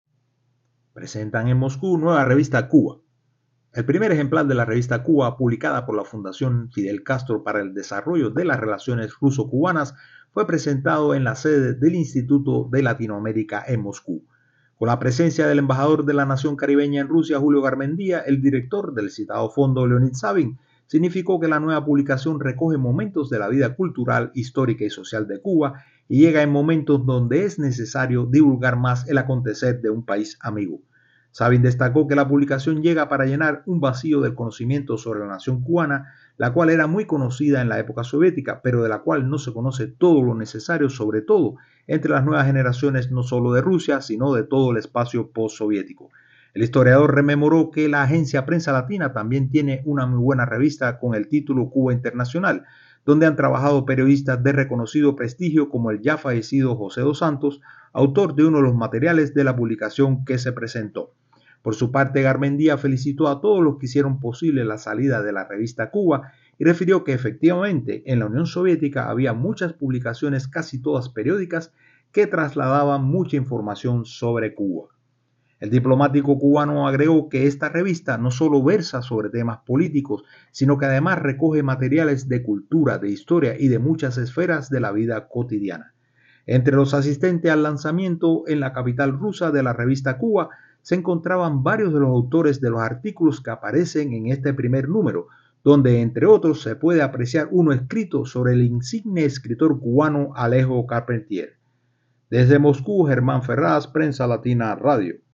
desde Moscú